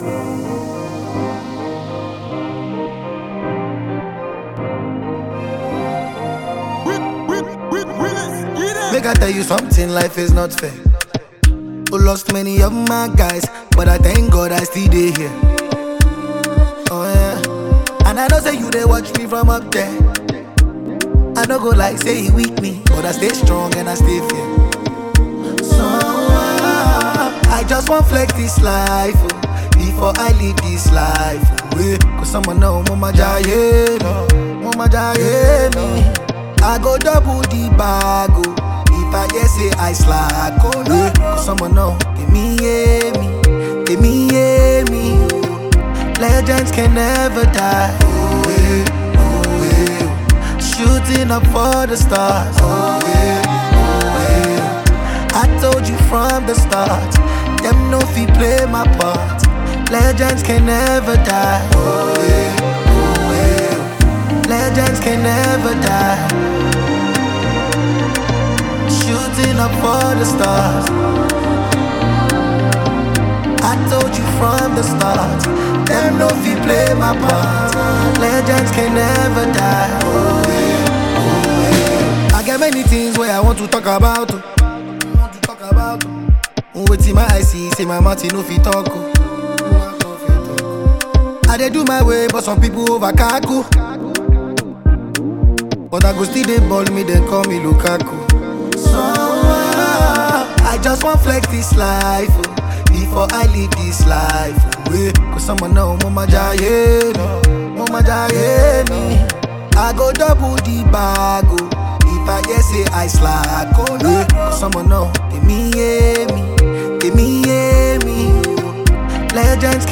The album mixes Afrobeats, amapiano, and highlife sounds.